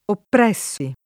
oppr$SSi] (raro opprimetti [